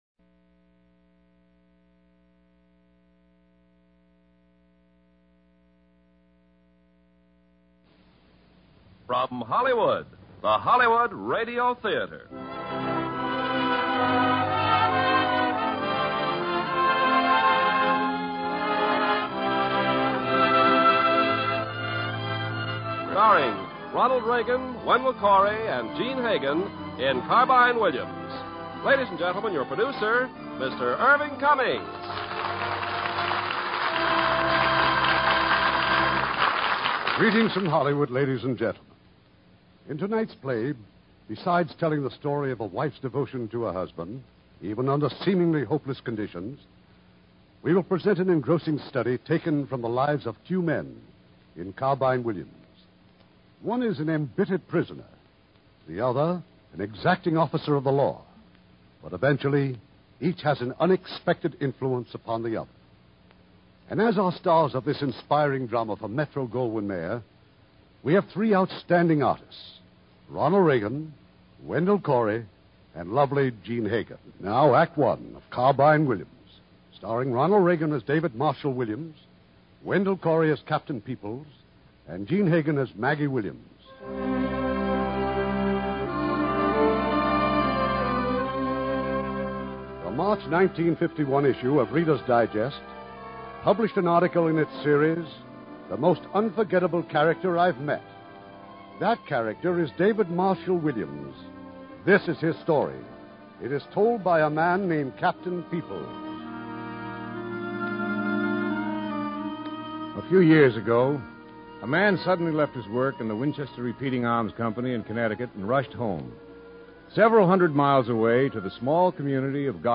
Carbine Williams, starring Ronald Reagan, Wendell Corey, Jean Hagen
Lux Radio Theater Radio Show